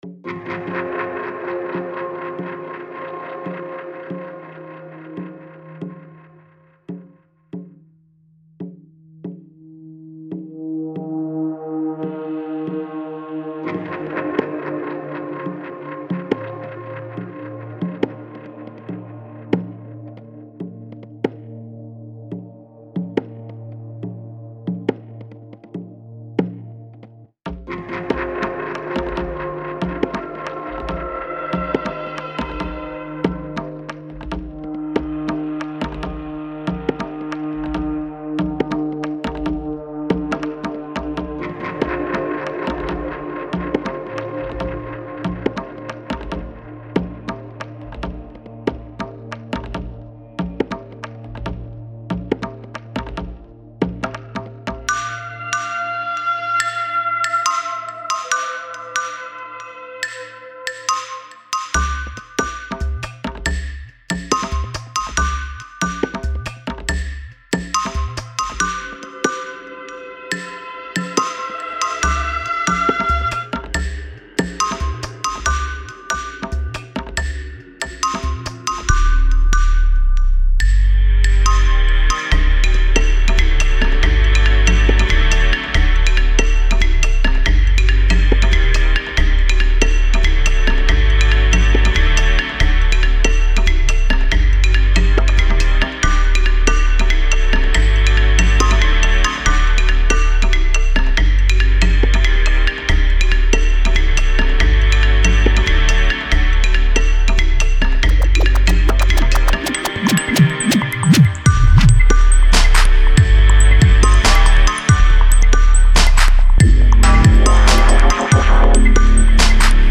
what is the first song in your mix with the xylophones?
Definitely loving that track, it really sets the mood.
dubstep mixes for the ipod